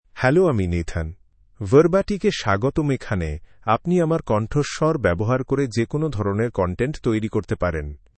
Nathan — Male Bengali AI voice
Nathan is a male AI voice for Bengali (India).
Voice sample
Listen to Nathan's male Bengali voice.
Nathan delivers clear pronunciation with authentic India Bengali intonation, making your content sound professionally produced.